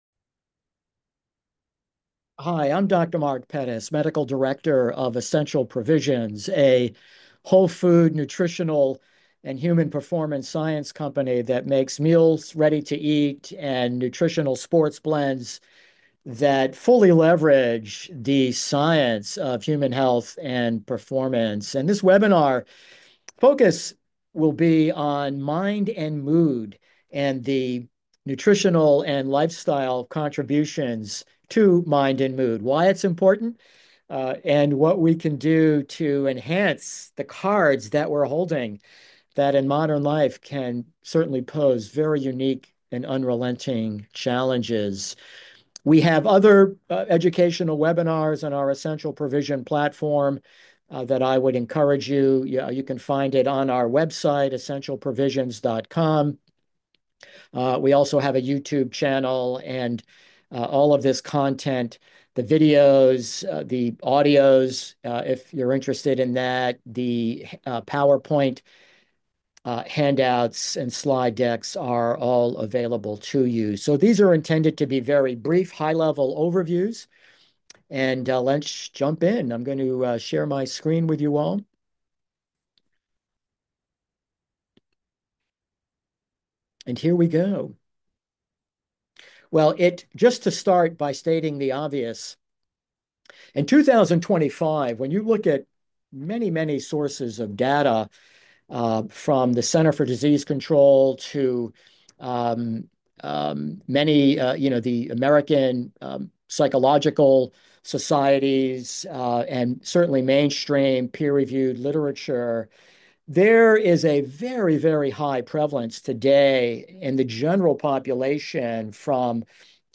EP-Webinar-Mind-and-Mood.mp3